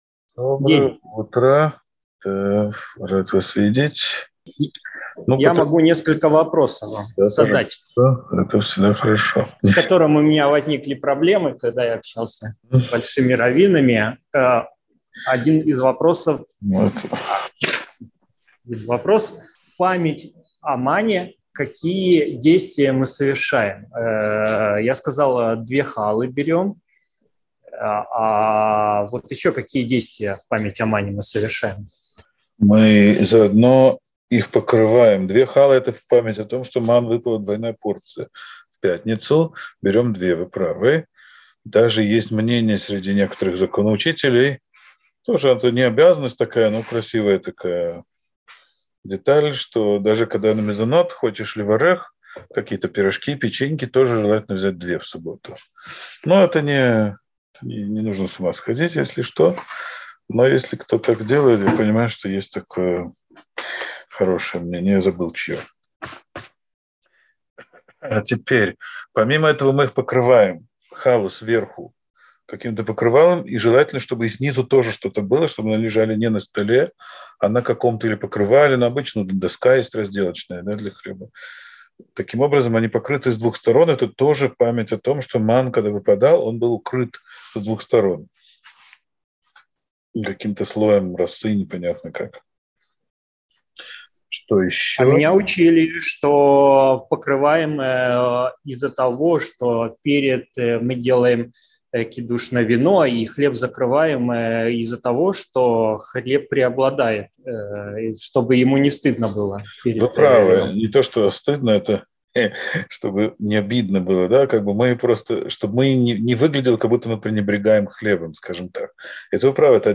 Сефер а-Хинух. Урок 77.